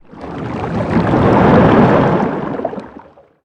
Sfx_creature_hiddencroc_swim_slow_01.ogg